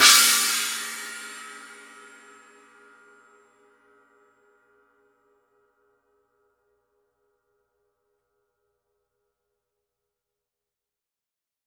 Paiste 14" RUDE Blast China Cymbal | Nicko's Drum One
RUDE continues to be the leading choice of sound for raw, merciless and powerful musical energy in Rock, Metal, and Punk